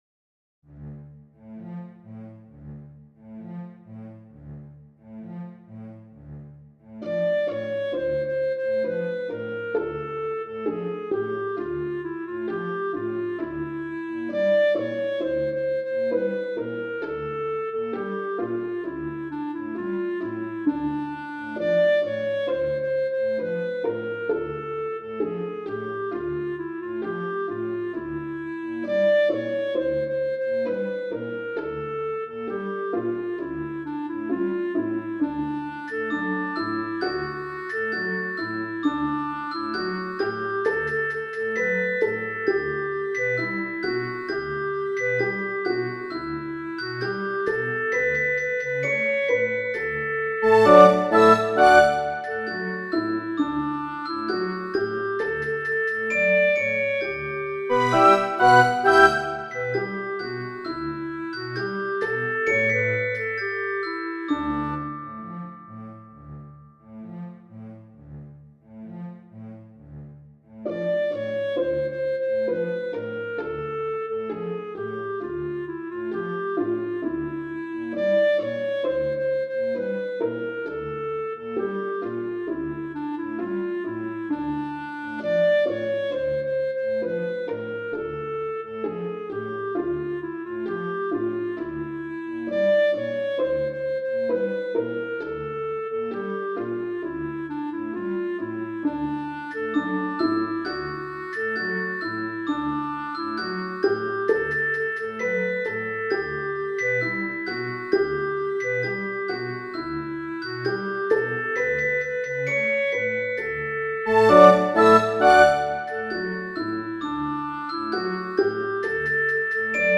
Lo proponiamo in versione didattica per flauto.